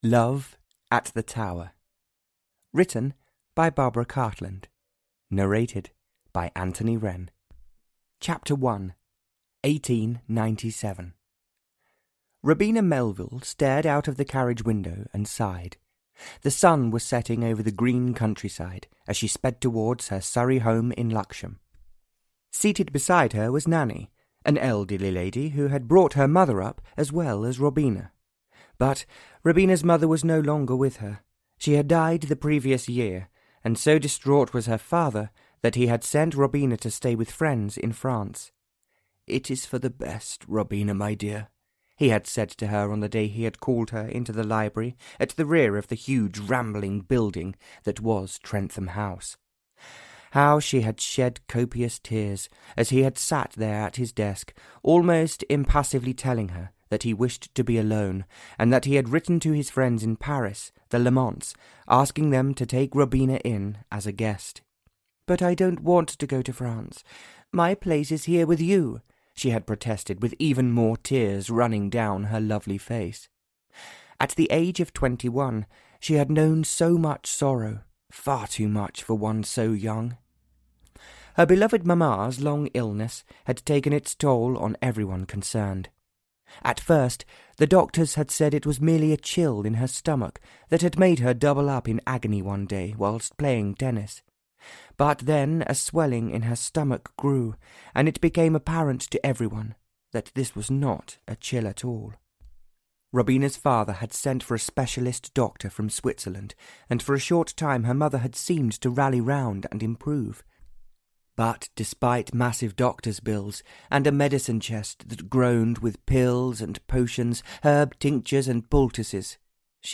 Love At The Tower (Barbara Cartland’s Pink Collection 54) (EN) audiokniha
Ukázka z knihy